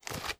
STEPS Dirt, Walk 26.wav